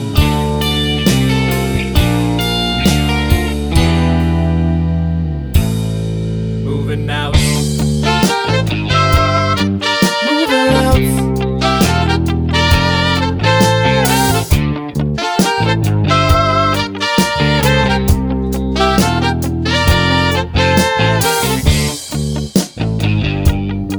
no piano Pop (1980s) 3:28 Buy £1.50